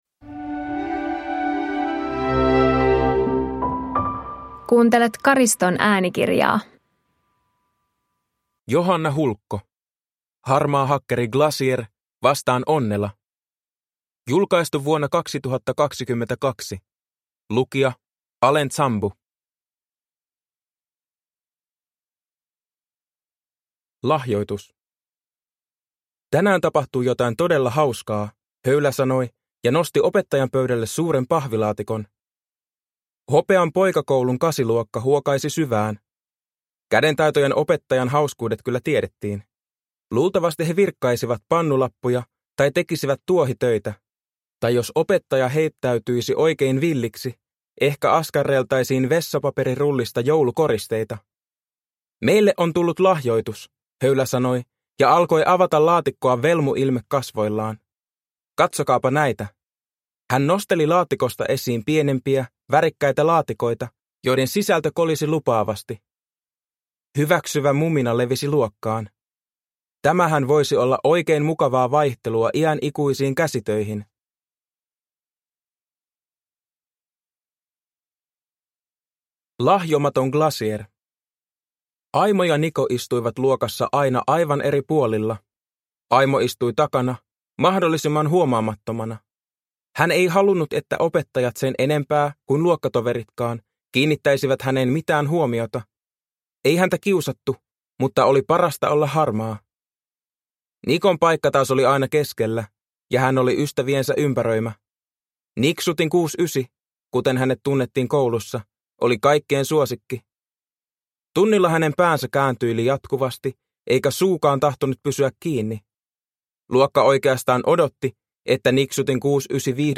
Harmaa hakkeri - Glasier vastaan Onnela – Ljudbok – Laddas ner